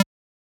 edm-perc-10.wav